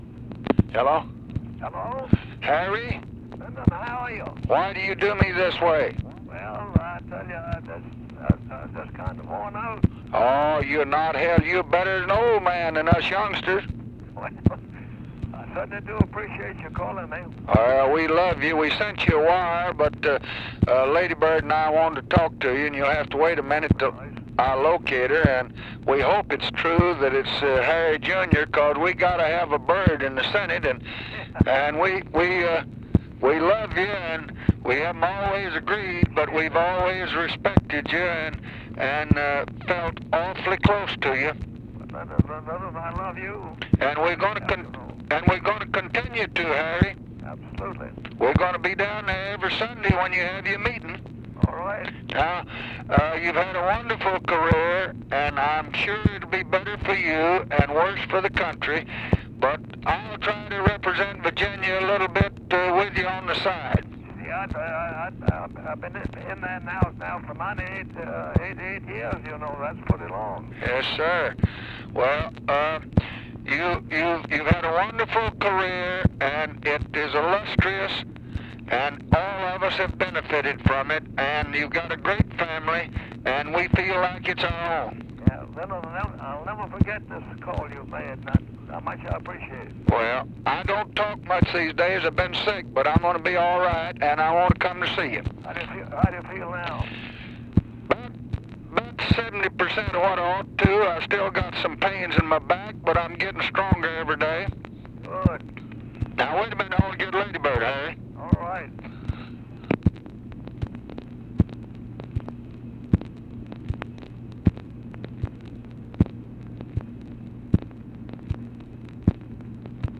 Conversation with HARRY BYRD, November 12, 1965
Secret White House Tapes